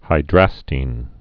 (hī-drăstēn, -tĭn)